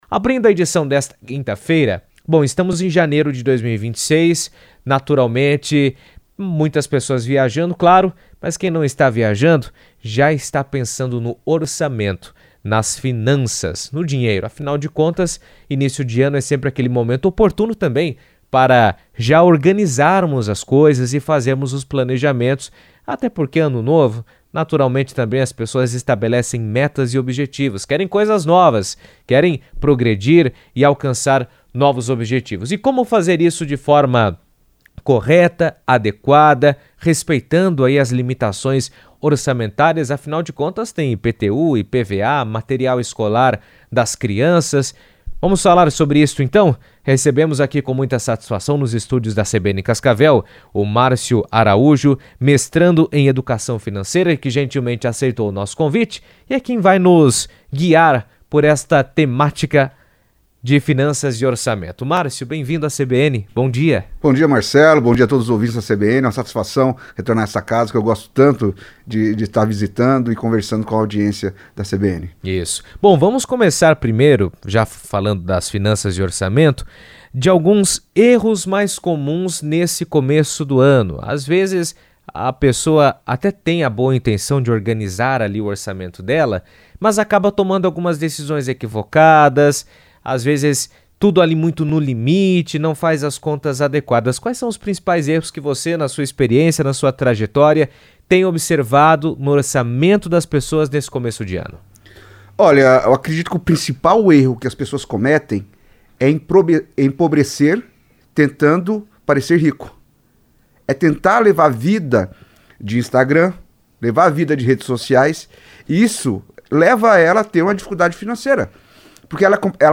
que falou sobre estratégias de organização e controle dos gastos em entrevista à CBN.